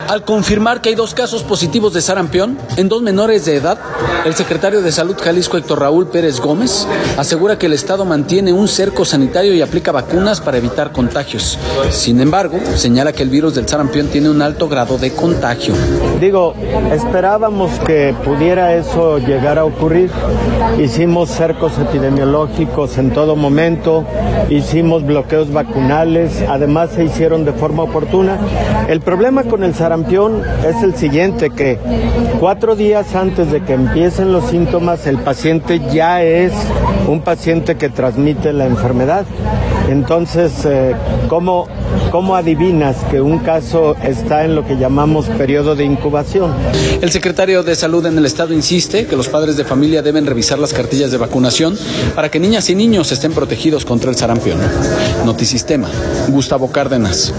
Al confirmar que hay 2 casos positivos de sarampión en 2 menores de edad, el secretario de Salud Jalisco, Héctor Pérez Gómez, asegura que el estado mantiene un cerco sanitario y aplica vacunas para evitar contagios.